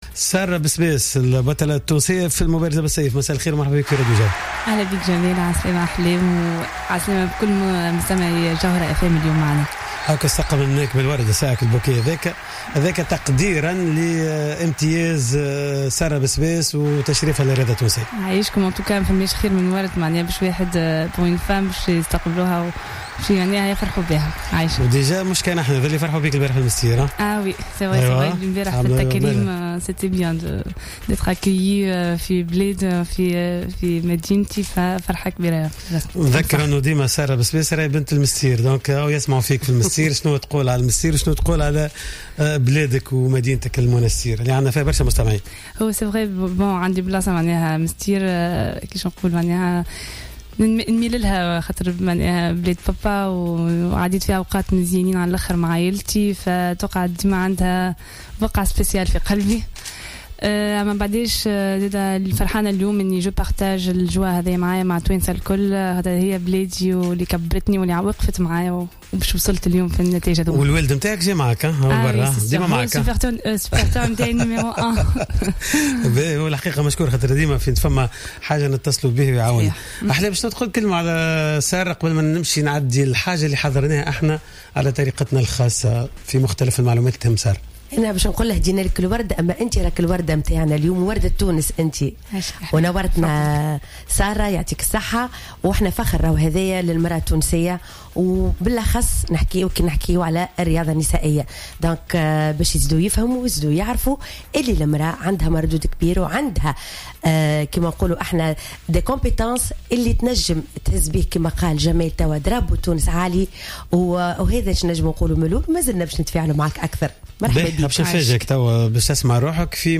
إستضاف برنامج "راديو سبور" اليوم الأحد البطلة العالمية في رياضة المبارزة بالسيف سارة بسباس التي تحدثت على إحرازها على لقب بطولة الجائزة الكبرى للمبارزة التي إحتضنتها العاصمة القطرية الدوحة مؤخرا .
سارة بسباس ضيفة راديو سبور